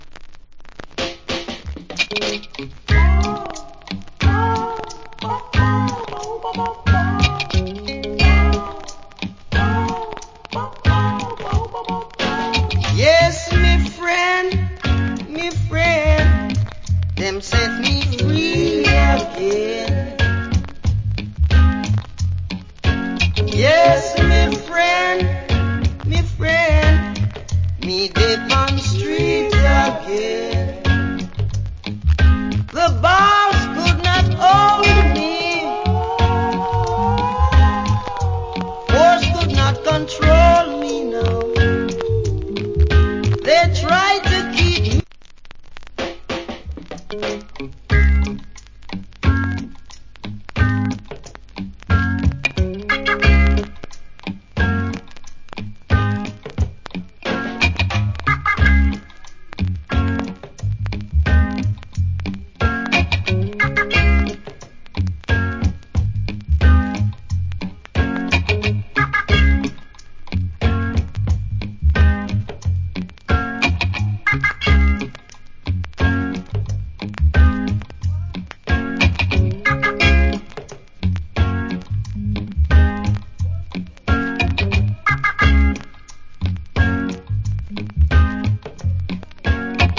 Roots Rock Vocal. / Version.